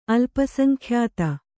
♪ alpasaŋkhyāta